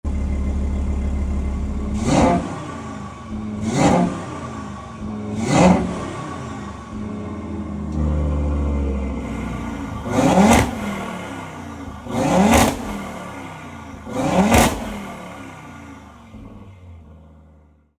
Listen to the 5-cylinder fury
• RS Sports Exhaust System with Black Oval Tips
• 2.5TFSI 5-Cylinder Turbo Engine
san-Marino-Revs.mp3